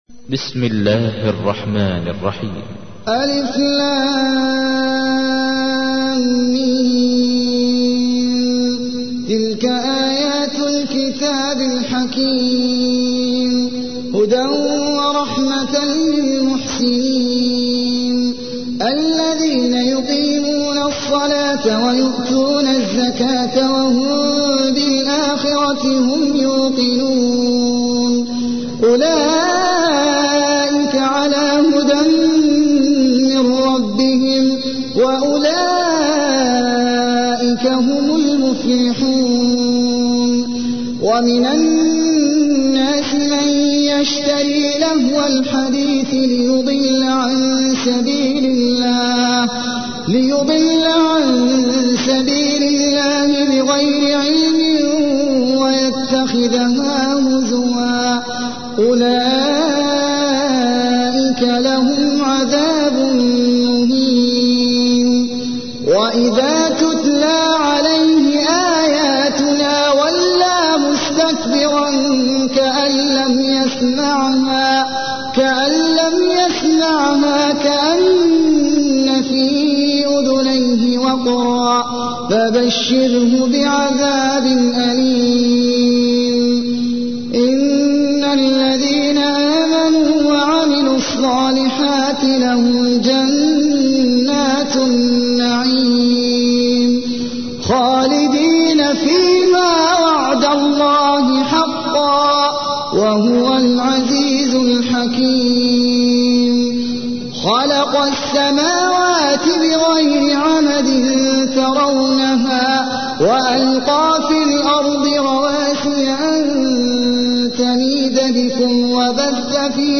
تحميل : 31. سورة لقمان / القارئ احمد العجمي / القرآن الكريم / موقع يا حسين